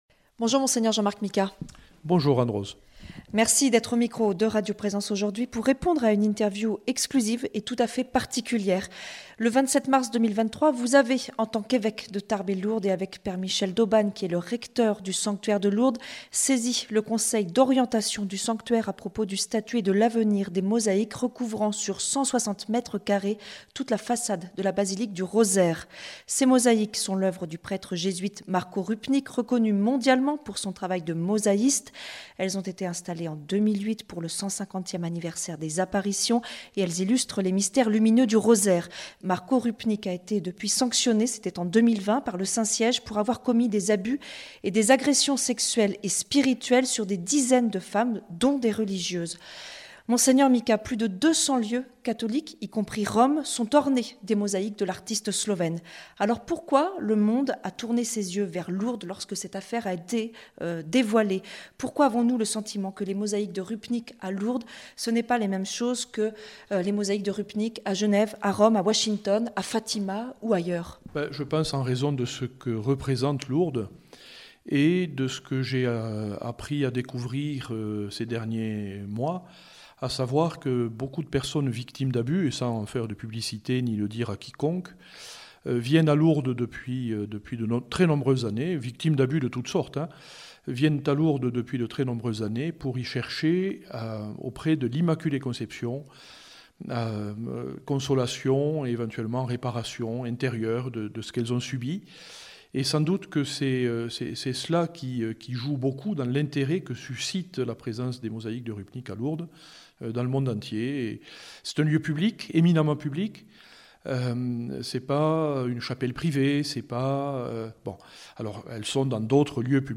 Communiqué de Monseigneur Jean-Marc Micas, évêque de Tarbes et Lourdes.